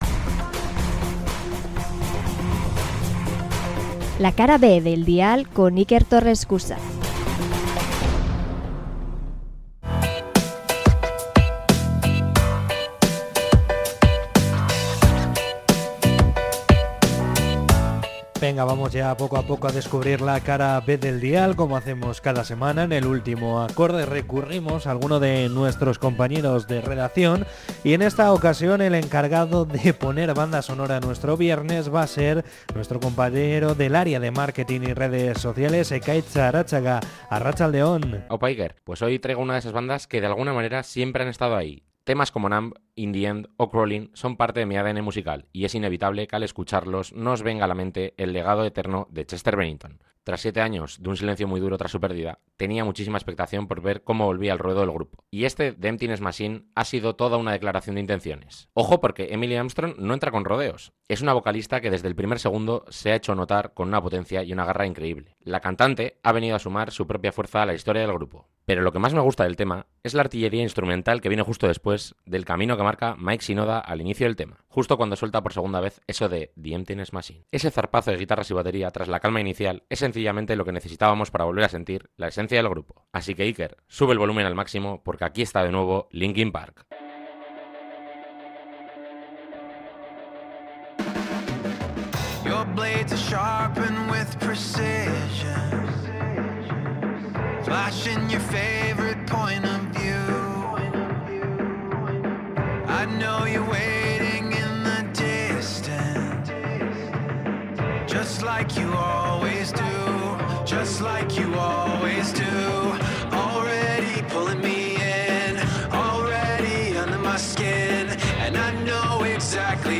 Podcast Bilbao